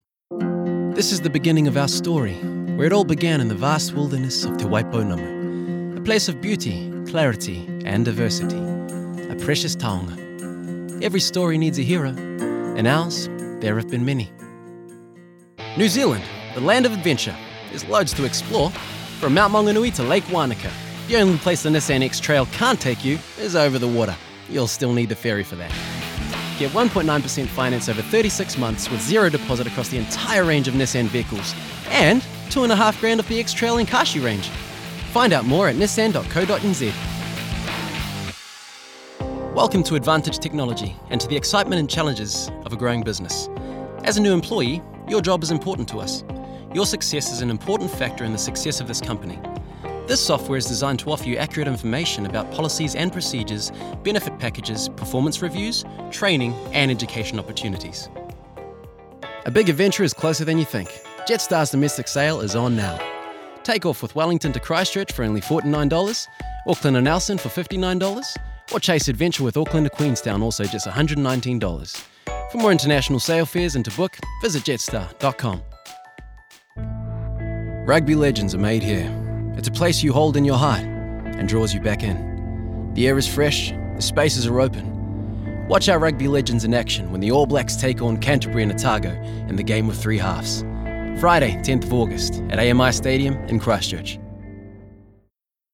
Demo
Adult
new zealand | natural